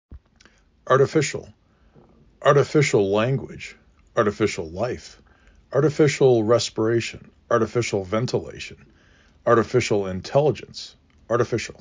ar · t ə · f i · S ə l
4 Syllables: ar-ti-FI-cial
Stressed Syllable: 3